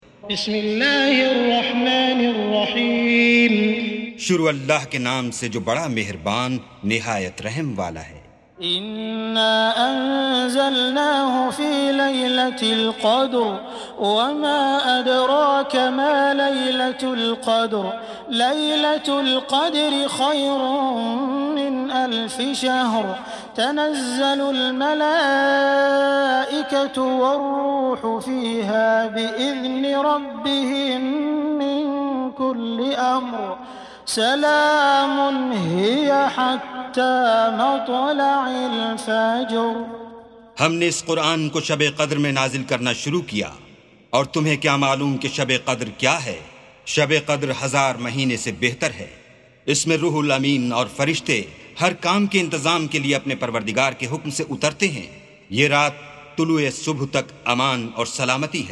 سُورَةُ القَدۡرِ بصوت الشيخ السديس والشريم مترجم إلى الاردو